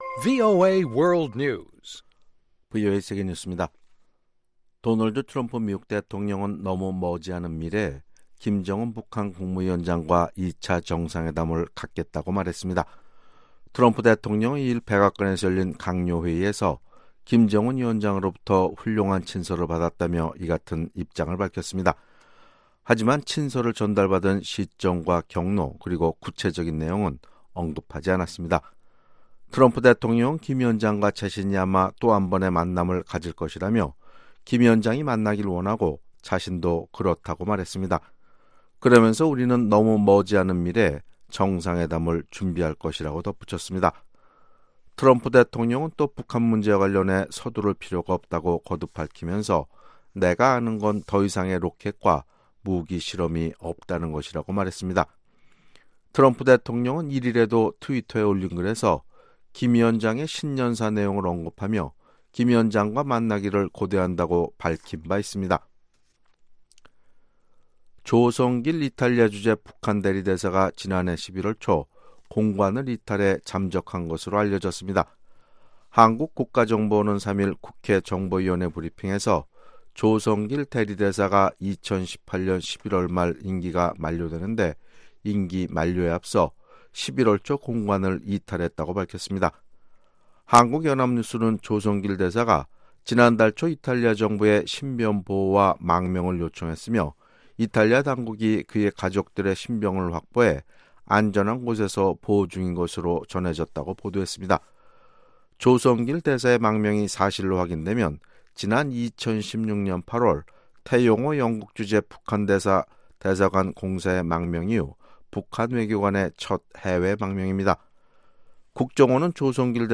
VOA 한국어 아침 뉴스 프로그램 '워싱턴 뉴스 광장' 2019년 1월 4일 방송입니다. 트럼프 미국 대통령은 김정은 국무위원장으로부터 친서를 받았다고 밝히고 2차 미-북 정상회담을 예고했습니다. 1월 유엔 안보리 의장으로 활동하게 된 도미니카 공화국 유엔 대사는 북한 문제 해결에는 “대화가 유일한 해법”이라고 말했습니다.